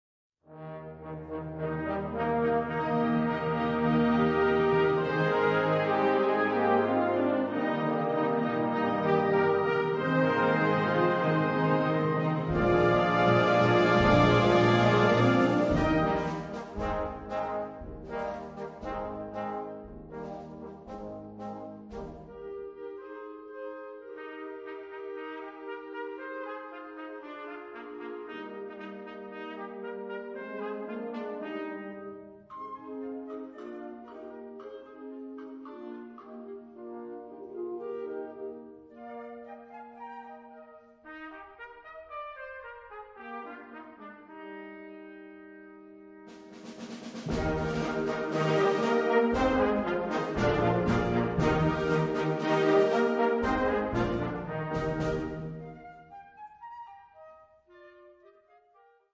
Catégorie Harmonie/Fanfare/Brass-band
Sous-catégorie Rhapsodies
Instrumentation Ha (orchestre d'harmonie)